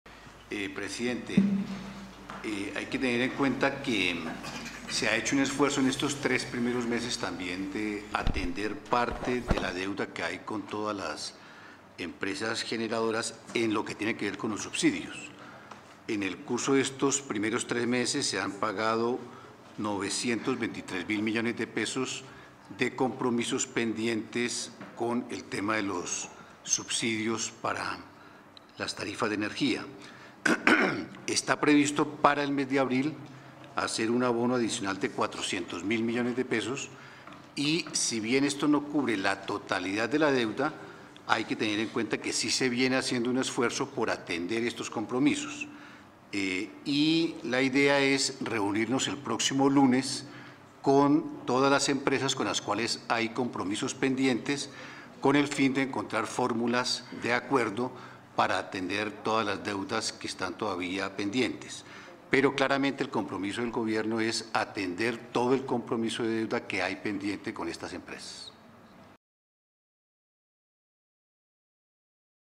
Intervención del ministro de Hacienda en el Consejo de Ministros 7 de abril
Stereo
intervencion-del-ministro-de-hacienda-en-el-consejo-de-ministros-7-de-abril-mp3